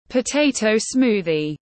Sinh tố khoai tây tiếng anh gọi là potato smoothie, phiên âm tiếng anh đọc là /pəˈteɪ.təʊ ˈsmuː.ði/
Potato smoothie /pəˈteɪ.təʊ ˈsmuː.ði/